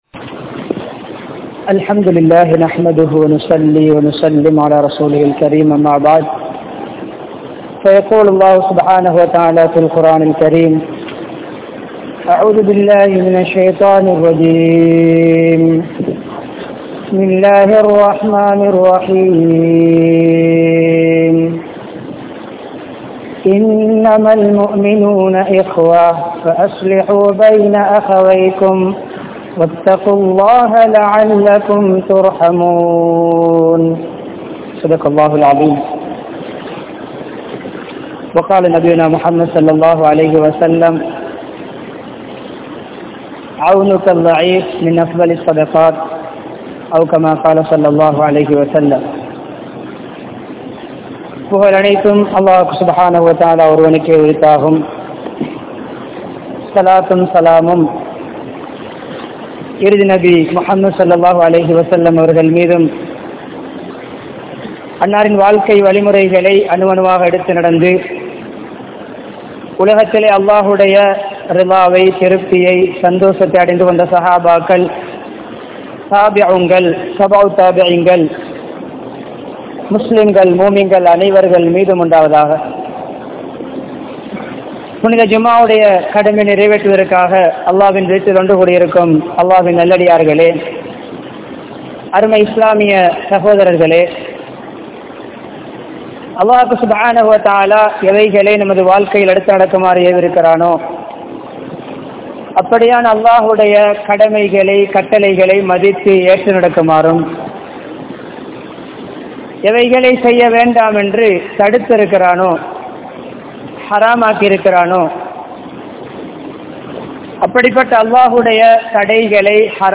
Pirarukku Uthavungal (பிறருக்கு உதவுங்கள்) | Audio Bayans | All Ceylon Muslim Youth Community | Addalaichenai